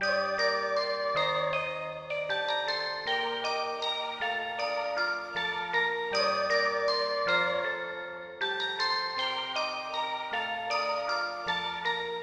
悠扬的钟声
标签： 157 bpm Hip Hop Loops Bells Loops 2.06 MB wav Key : Gm FL Studio
声道立体声